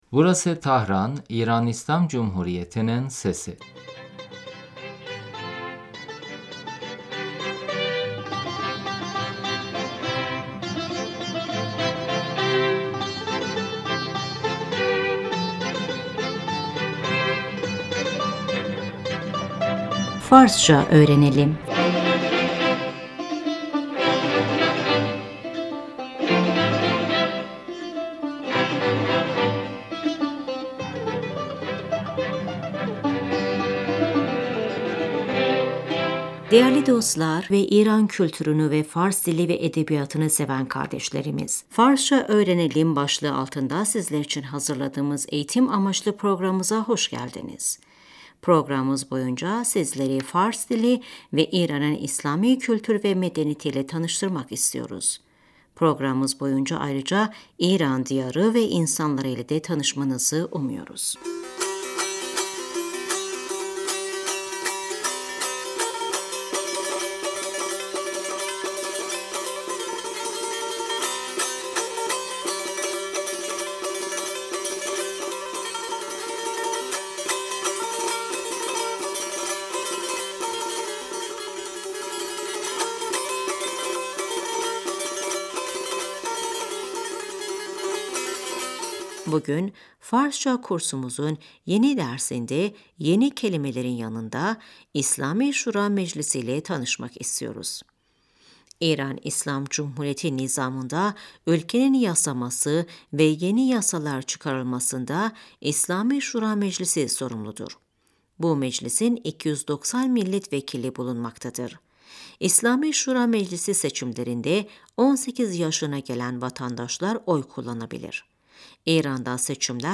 Şimdi bugünkü dersimizin ilk diyaloğunu dinleyelim.